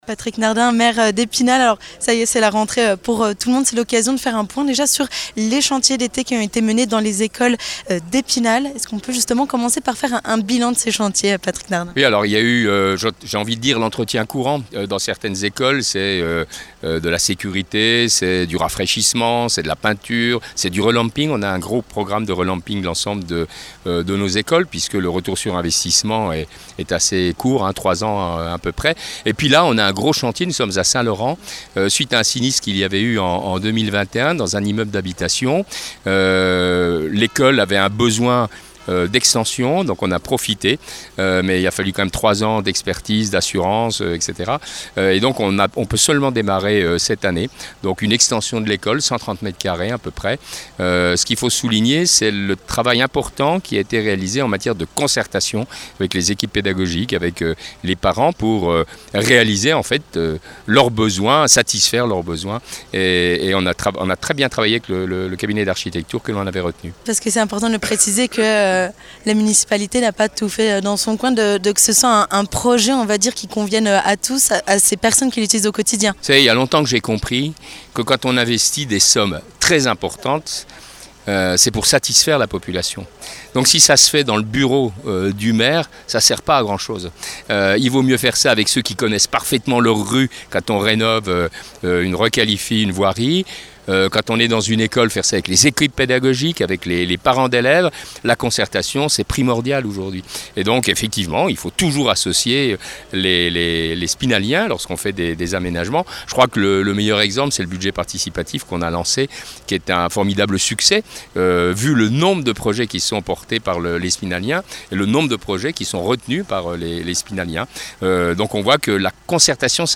Pour en savoir plus sur ce projet d'extention, retrouvez l'interview de Patrick Nardin, maire d'Epinal.